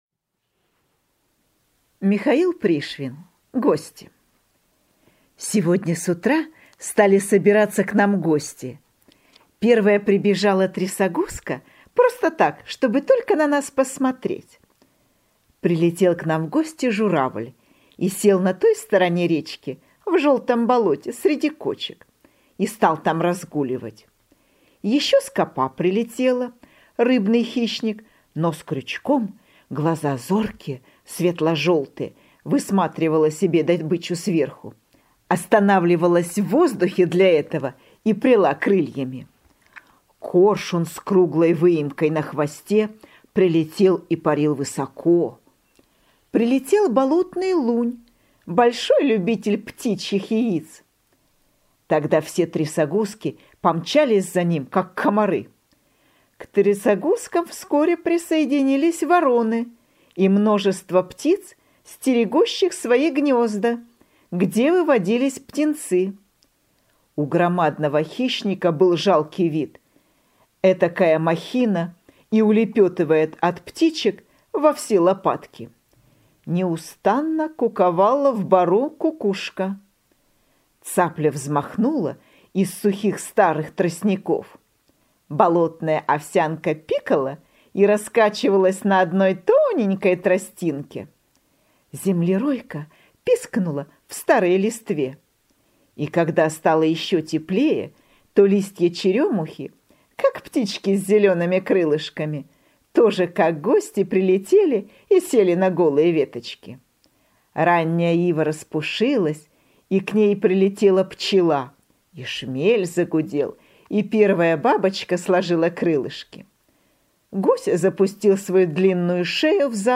Гости - аудио рассказ Пришвина - читать онлайн | Мишкины книжки
Гости – Пришвин М.М. (аудиоверсия)